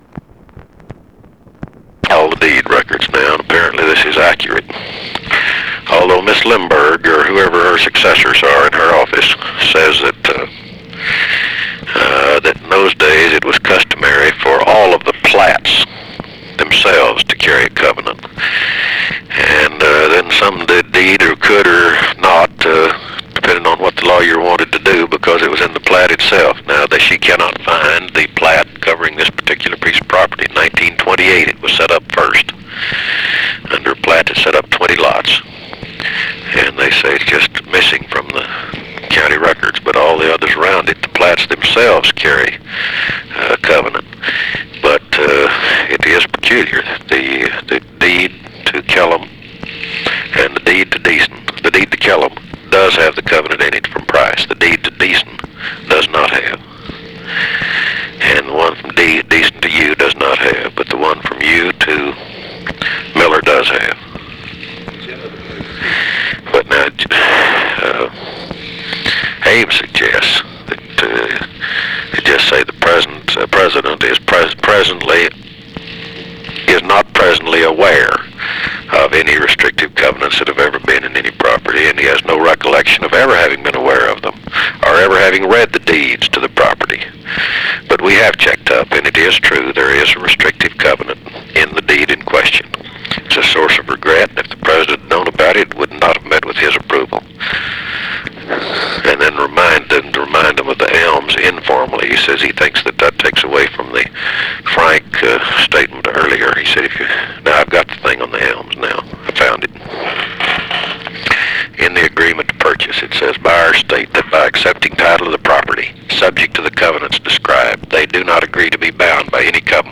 Conversation with WALTER JENKINS, September 16, 1964
Secret White House Tapes